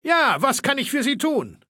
Fallout 3: Audiodialoge